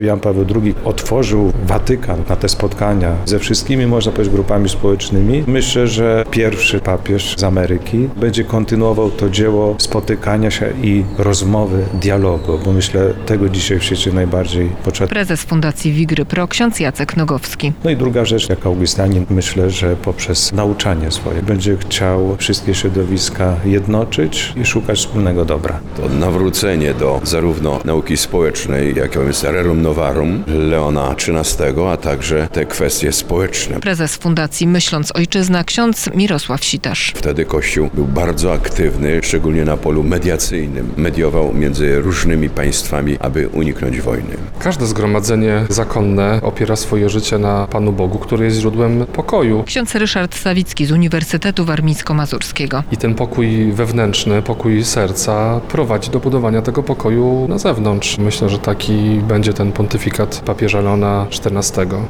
Ogólnopolska Konferencja Naukowa odbywa się w Klasztorze Pokamedulskim w Wigrach w ramach IX Międzynarodowego Kongresu Ruchu Europa Christi "Przyszłość Europy".